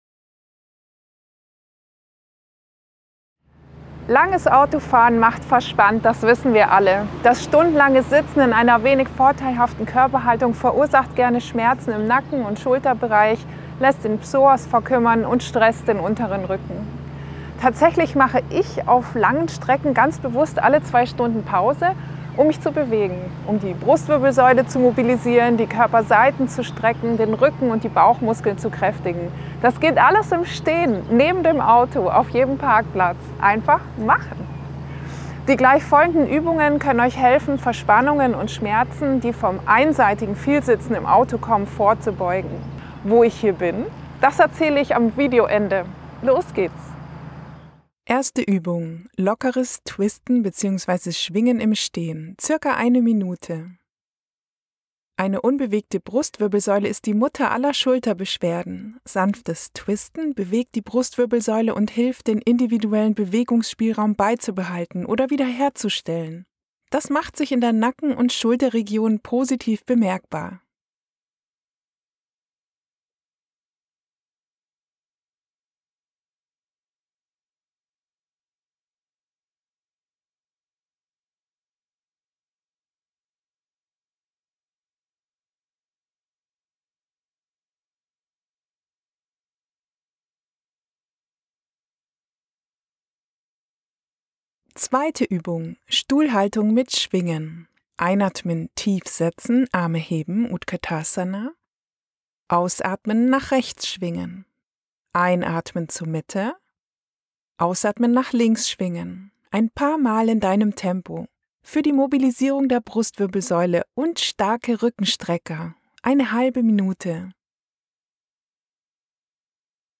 Irgendwo mitten in Frankreich auf einem klassischen Rastplatz, auf dem Weg an den Atlantik. Der Wind war sehr frisch und zwei Riesenrasenmäher fingen genau bei Drehbeginn an, Lärm zu machen.